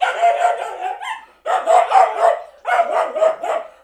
DOG 2.WAV